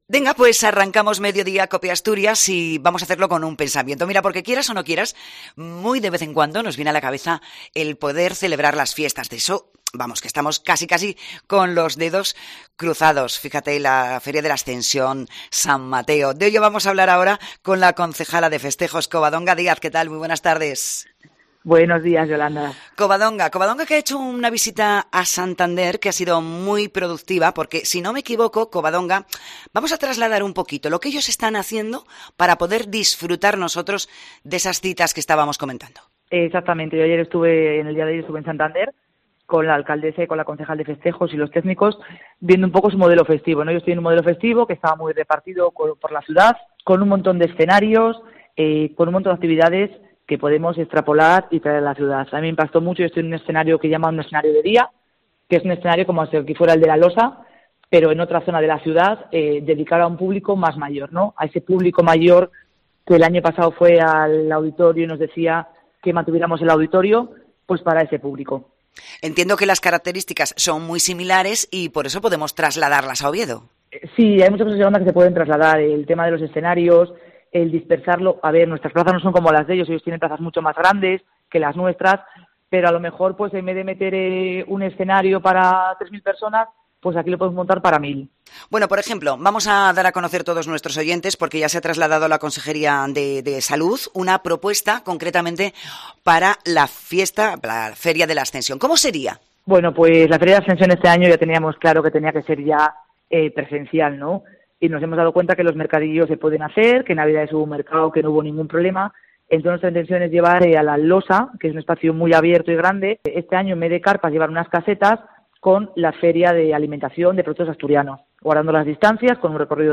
Covadonga Díaz, Concejala de Festejos del Ayuntamiento de Oviedo